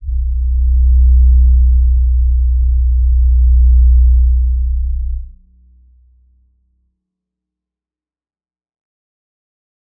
G_Crystal-B1-mf.wav